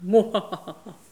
Les sons ont été découpés en morceaux exploitables. 2017-04-10 17:58:57 +02:00 194 KiB Raw Permalink History
mouhahaha_03.wav